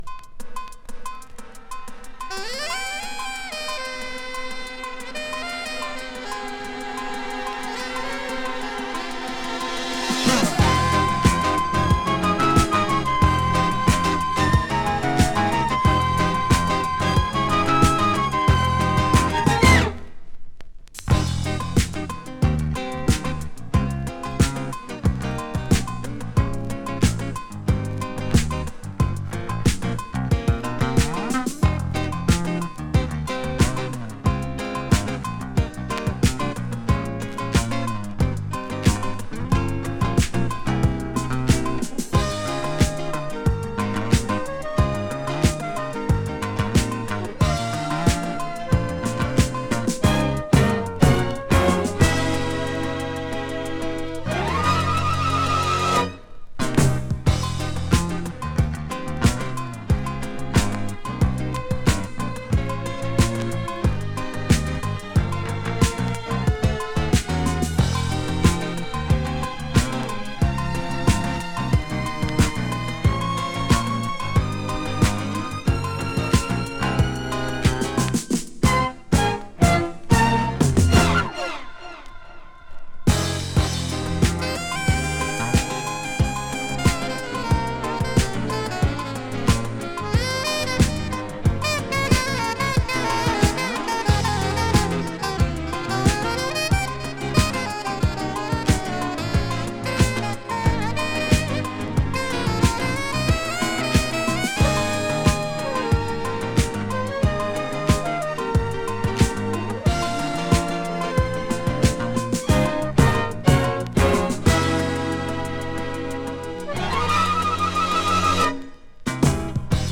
UK Mellow Disco！
【SYNTH POP】